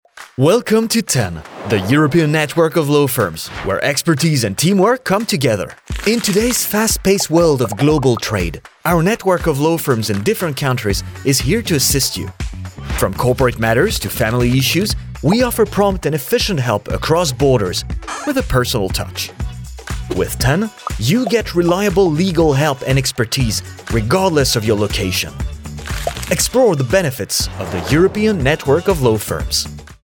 Explainer Videos
My natural voice lies in the middle-low range and is perfect to inspire and create trust with a touch of warmth and accessibility – ideal for corporate jobs, e-learnings and other “serious” projects that demand a reliable & inspiring yet warm and friendly delivery.